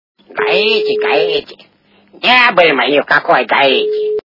При прослушивании Возвращение блудного попугая - Гаити Гаити... Не были мы ни в какой Гаити качество понижено и присутствуют гудки.